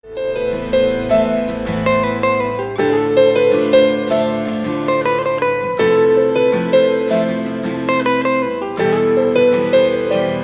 bollywood somgs